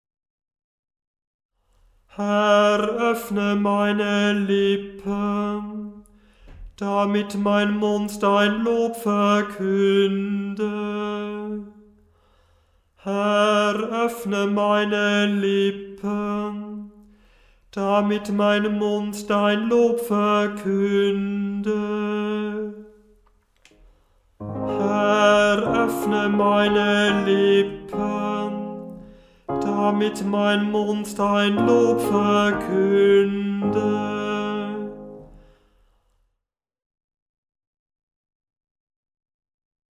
Beim Stundengebet übernimmt nach der Einleitung ein Kantor/eine Kantorin das Anstimmen der Gesänge.
Beim Stundengebet selber wird der Ruf natürlich nur einmal gesungen, die mehrmaligen Wiederholunge mögen das Üben erleichtern.
Herr, öffne meine Lippen, Gl 614,1 976 KB Erster Ton as, eine kleine Sekunde tiefer Herr, öffne meine Lippen, Gl 614,1 744 KB Erster Ton g, eine große Sekunde tiefer Herr, öffne meine Lippen, Gl 614,1 723 KB Erster Ton fis, eine kleine Terz tiefer